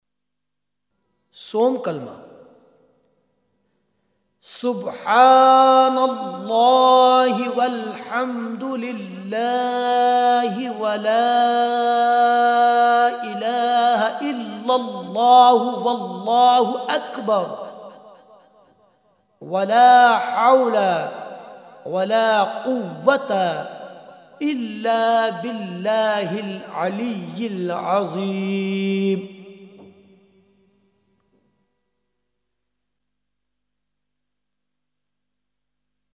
6 Kalima in Islam – Arabic Recitation
3rd-Qalma-Tamjeed.MP3